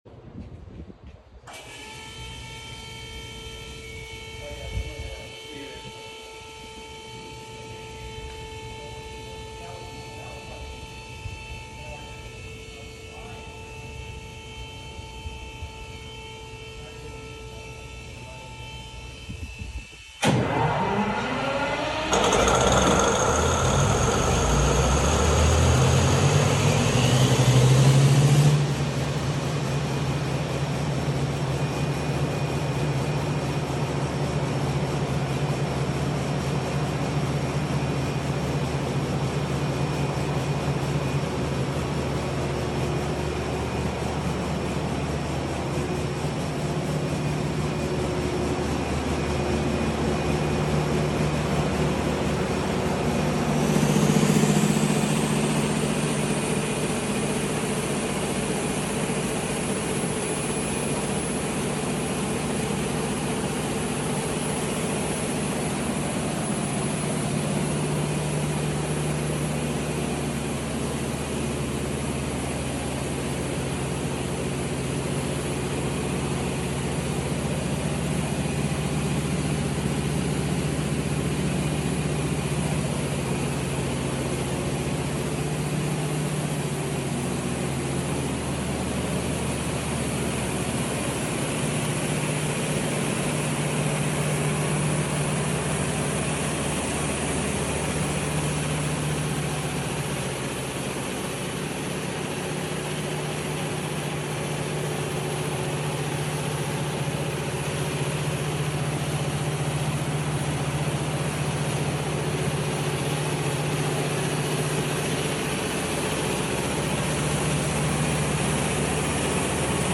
4 MW Caterpillar start, add sound effects free download
Yes the radiator is 12.5 feet tall! Love that diesel and turbo sound.